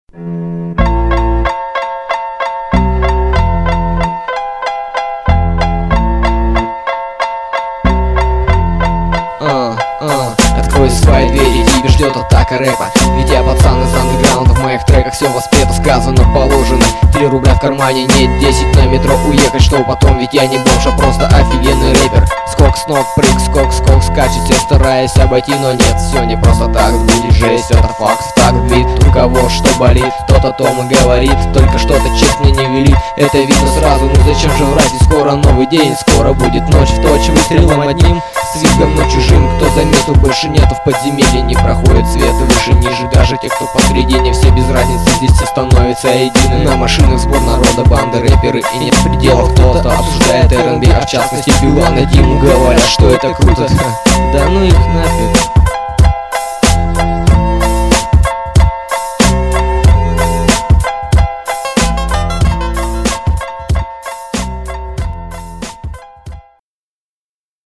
Rap battle